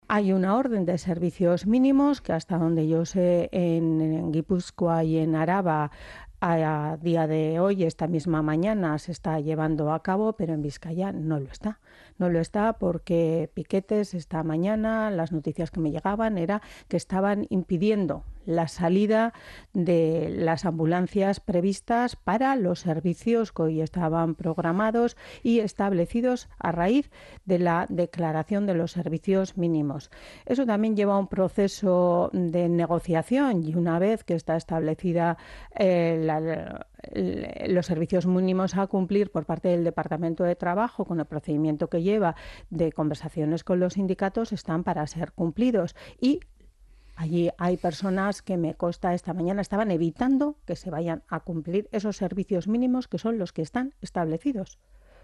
Gotzone Sagardui en los estudios de Onda Vasca